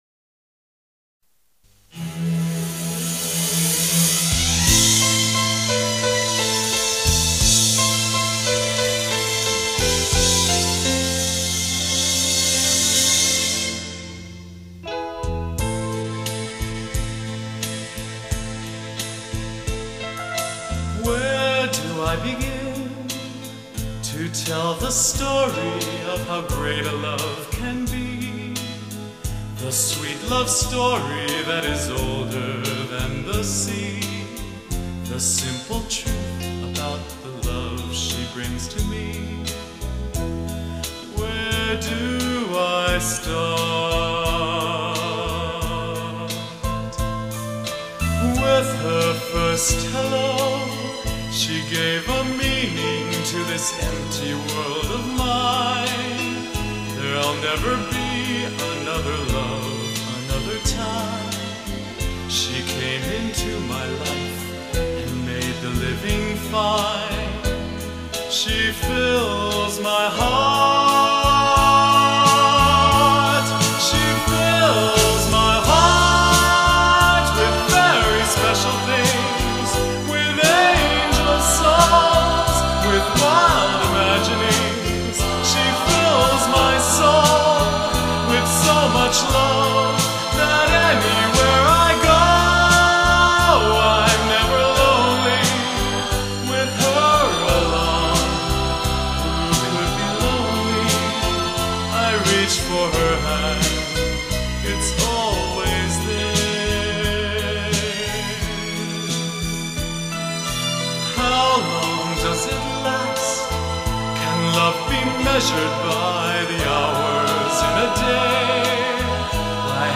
悲而不伤,豁达大气,这在其他歌手中是难得一见的.....